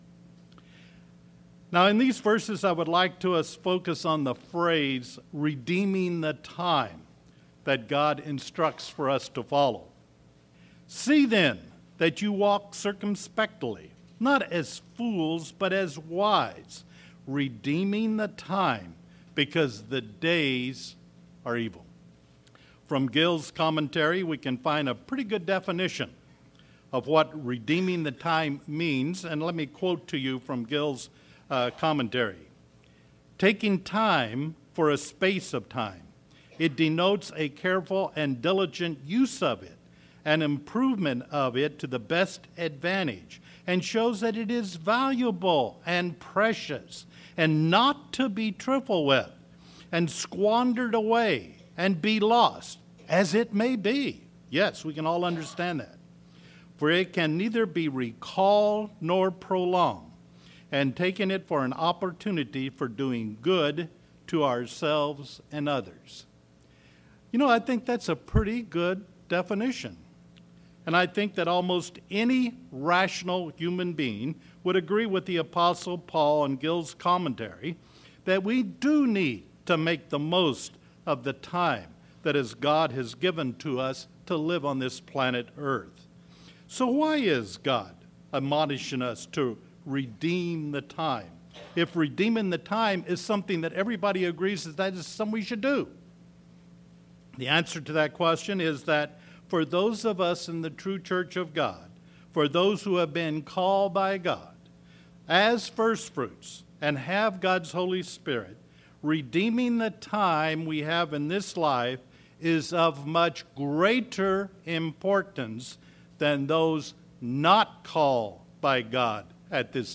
Sermons
Given in Redlands, CA